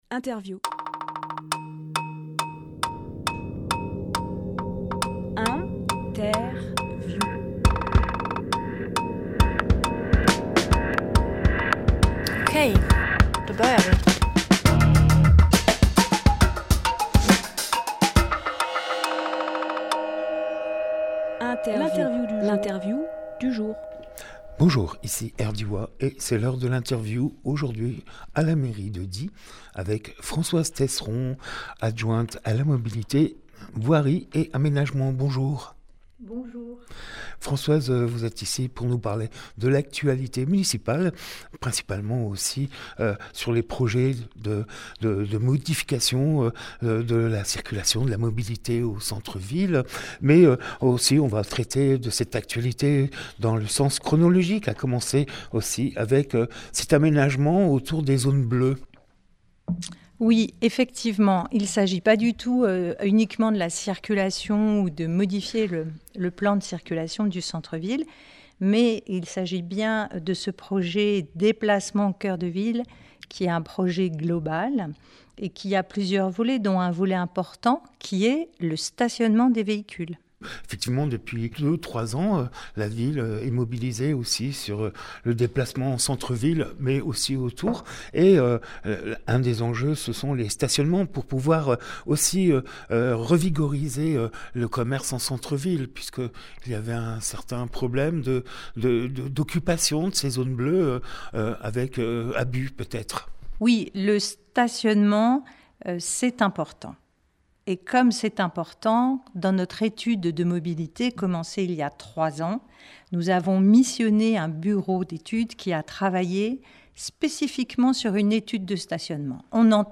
Emission - Interview Déplacements cœur de ville Publié le 31 mai 2024 Partager sur…
Lieu : Mairie de Die